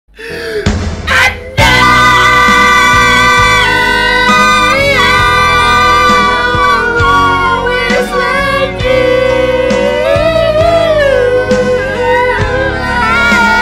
หมวดหมู่: เสียงเรียกเข้า